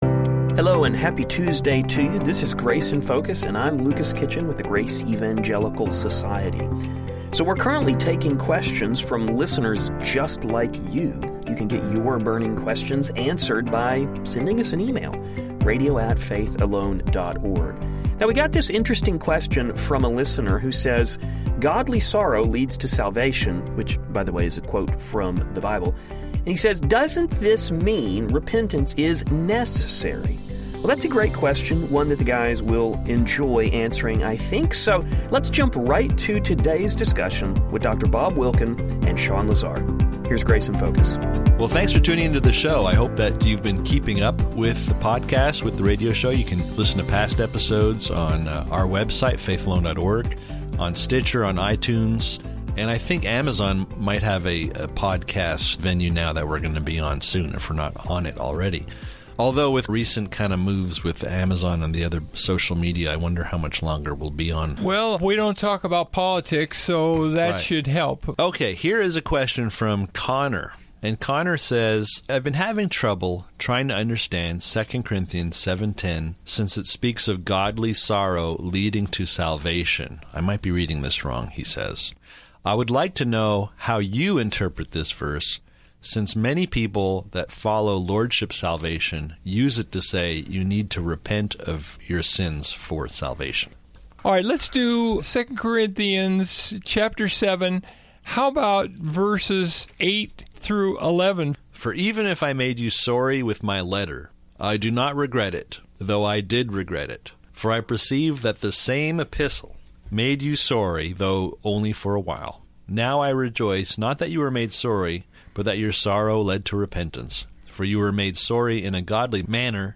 This verse is often used to say that repentance is necessary for salvation from hell. The guys will spend some time looking at this verse in context to see if that interpretation is correct. They will also be discussing the term “worldliness.”